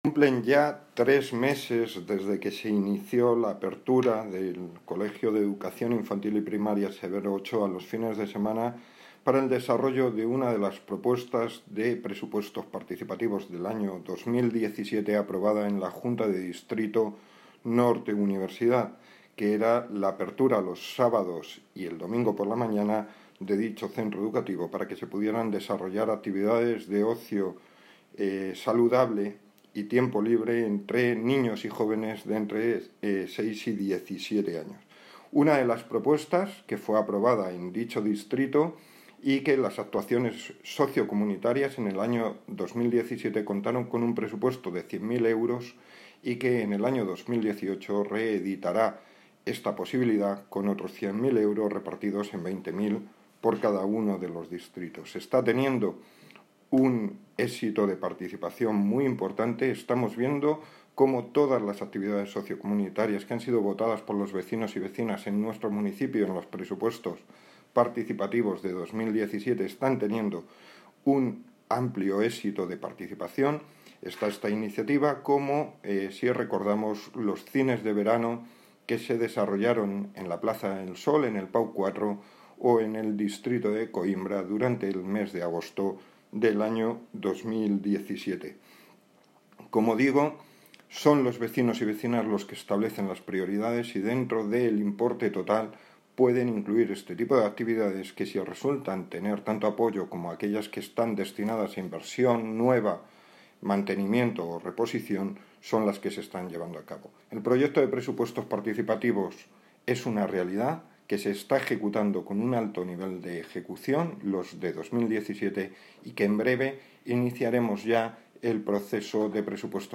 Audio - Francisco Javier Gómez (Concejal de Hacienda, Transporte y Movilidad) Sobre Centros Escolares